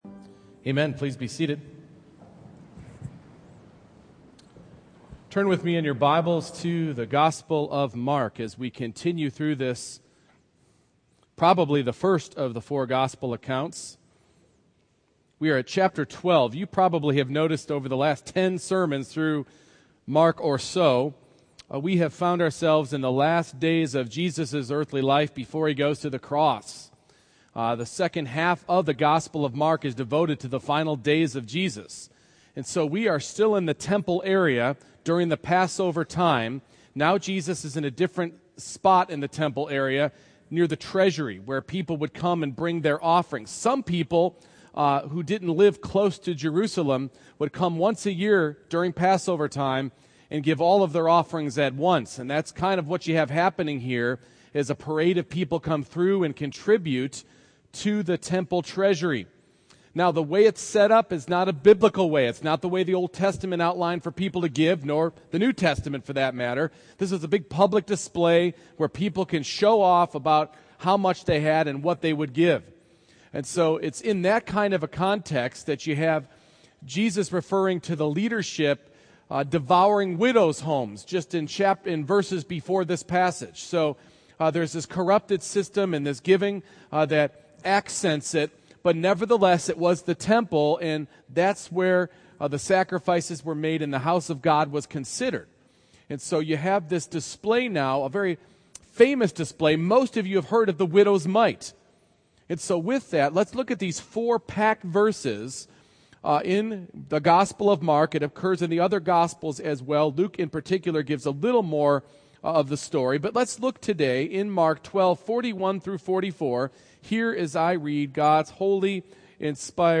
Mark 12:41-44 Service Type: Morning Worship Giving is an accurate diagnostic about the condition of our heart.